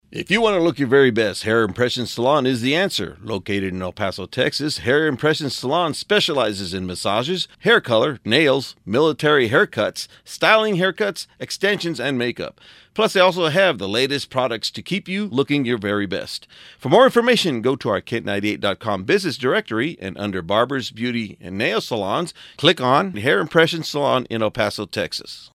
hairimpressionsspot.mp3